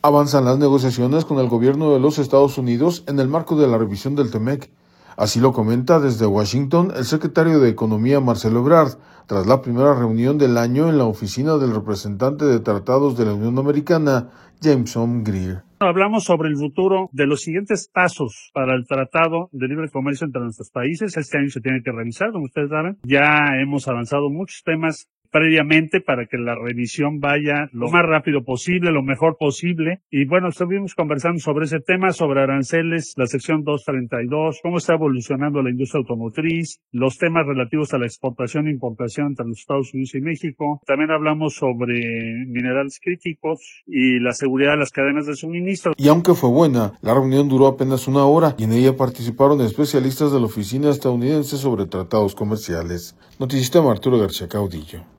Avanzan las negociaciones con el Gobierno de los Estados Unidos en el marco de la revisión del TMEC, así lo comenta desde Washington, el secretario de Economía, Marcelo Ebrard, tras la primera reunión del año en la oficina del representante de tratados de la Unión Americana, Jamieson Greer.